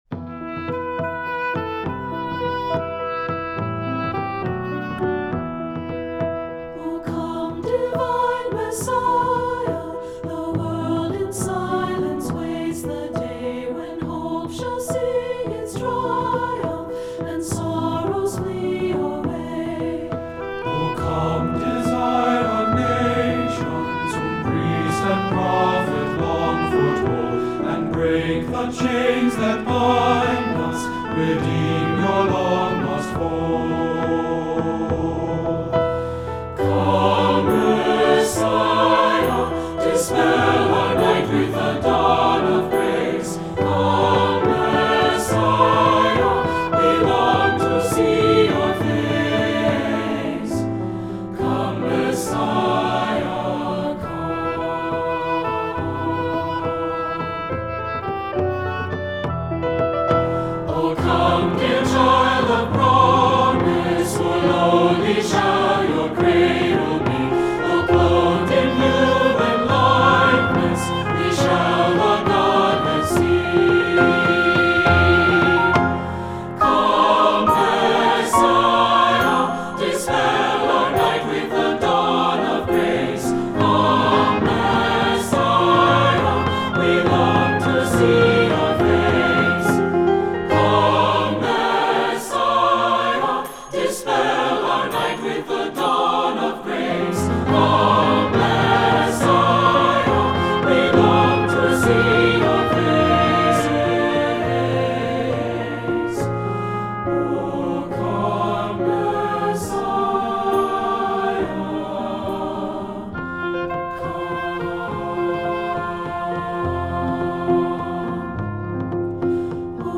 This buoyant Advent anthem
SATB